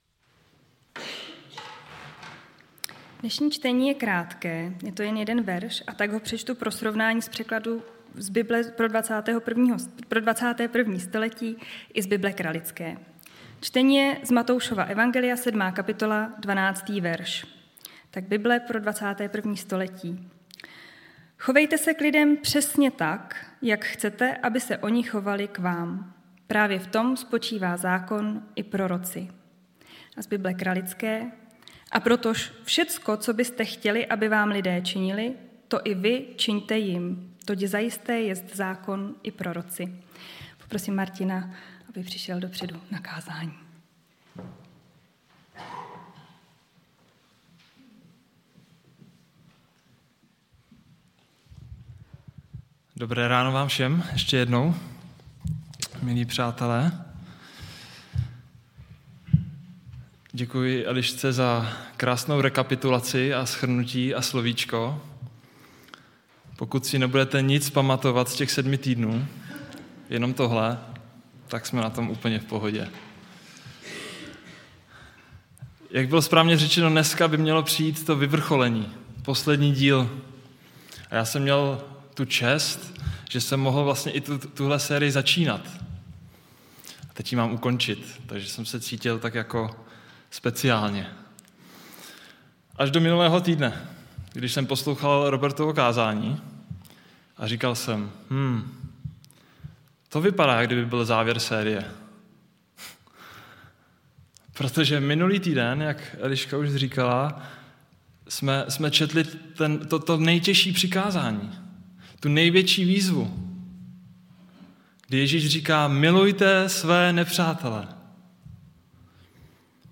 Nedělní bohoslužby přehrát